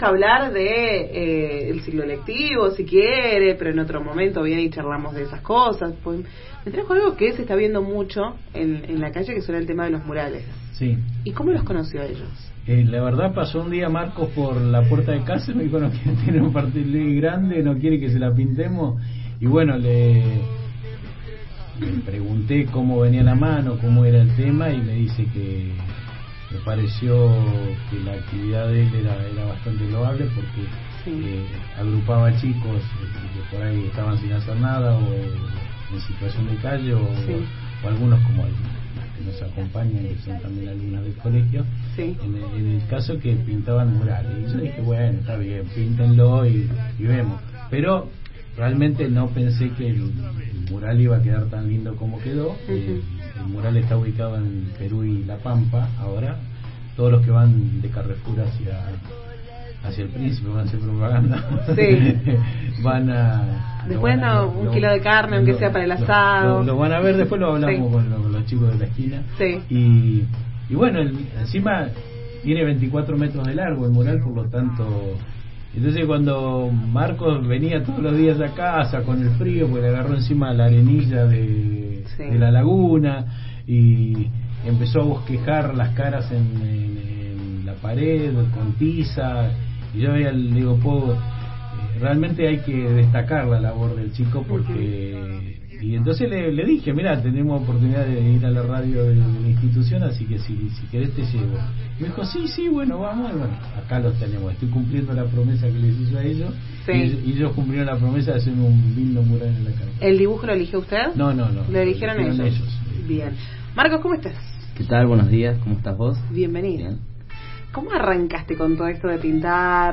visitó los estudios de Radio Universidad (93.5)